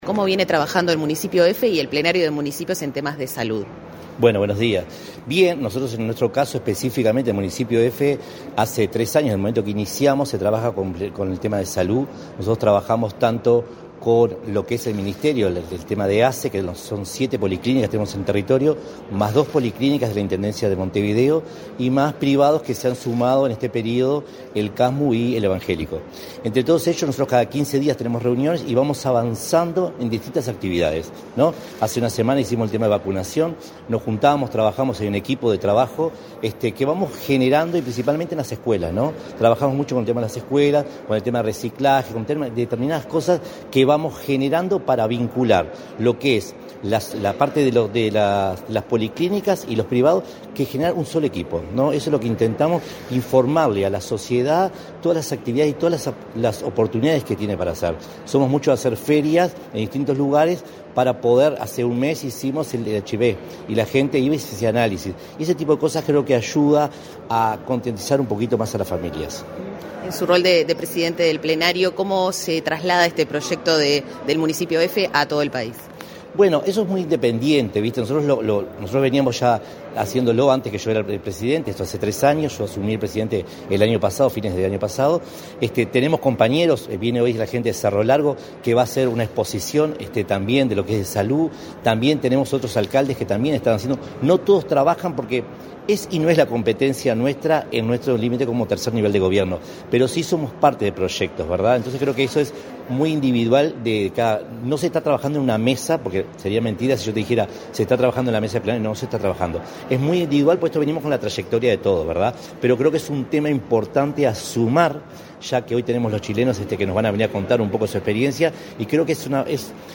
Entrevista al presidente del Plenario de Municipios, Juan Pedro López
Autoridades de la Oficina de Planeamiento y Presupuesto (OPP) recibieron, este 28 de mayo, a autoridades de la Asociación de Municipalidades de Chile y el Plenario de Municipios de Uruguay para intercambiar experiencias sobre temas sanitarios. Tras el evento, el presidente del Plenario de Municipios de Uruguay, Juan Pedro López, realizó declaraciones a la Comunicación Presidencial.